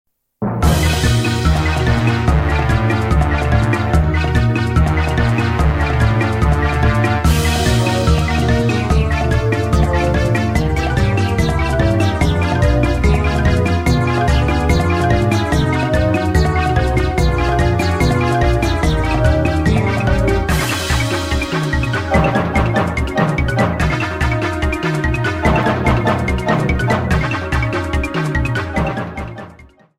30 seconds and fadeout You cannot overwrite this file.